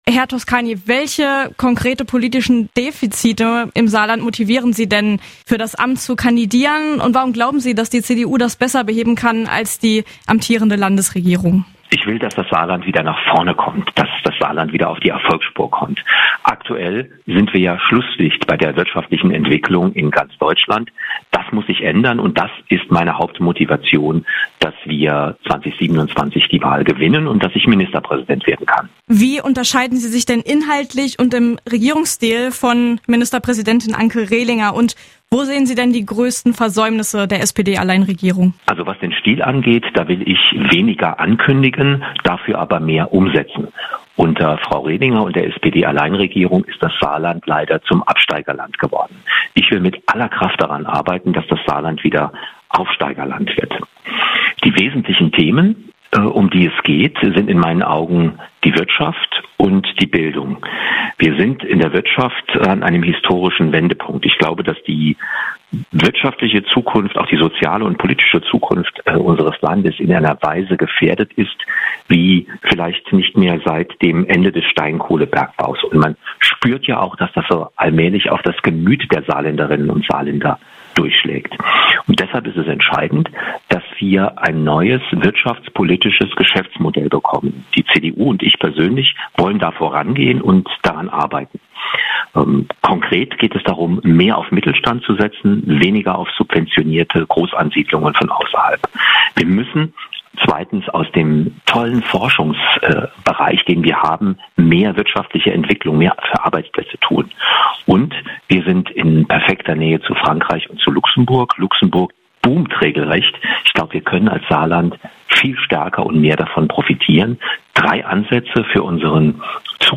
Das hört ihr jetzt im Gespräch mit